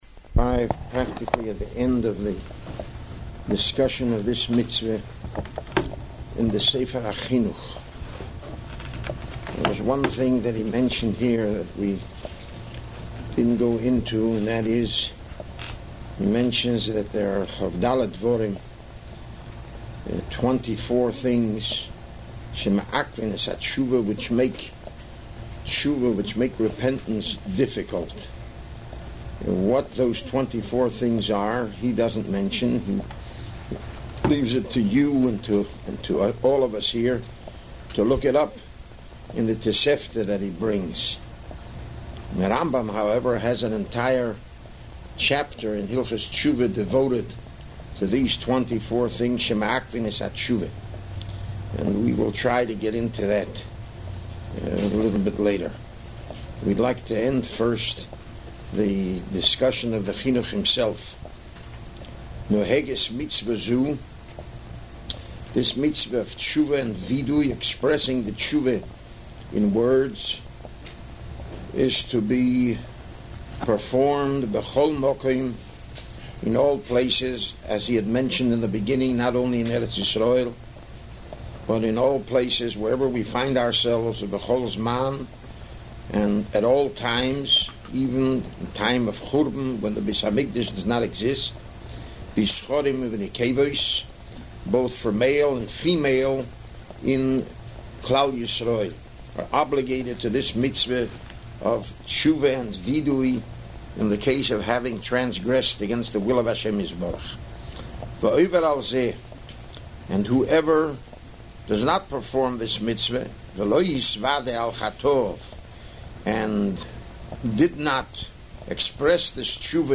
giving a shiur on Minchas Chinuch the Mitzvah of Teshuvah Part II.